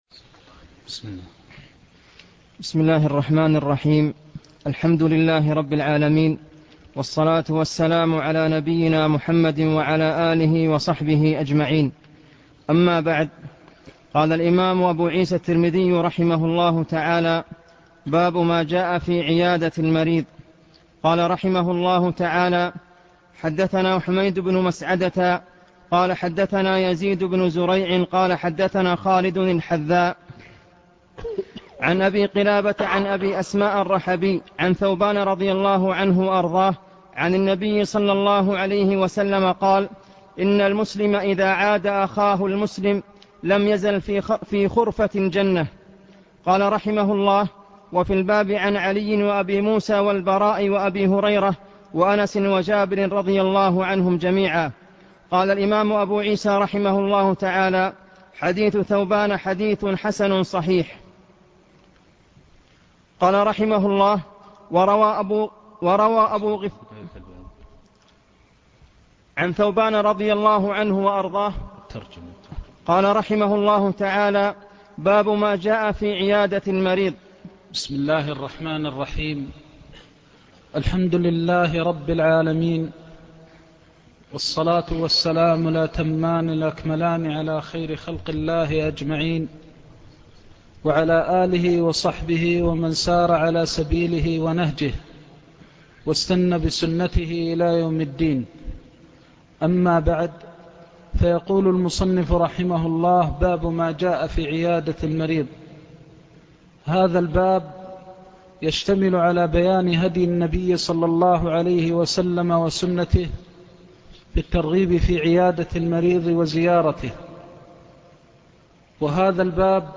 كتاب الجنائز الدرس الثاني باب مَا جَاءَ فِي عِيَادَةِ الْمَرِيضِ